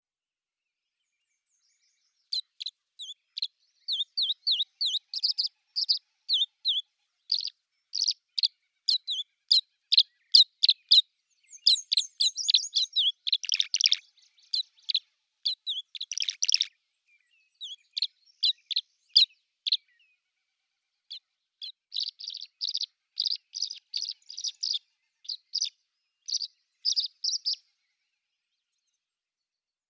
Вы услышите их щебет, трели и другие голосовые реакции, которые помогут вам ближе познакомиться с этими удивительными птицами.
Так звучит песня древесной американской ласточки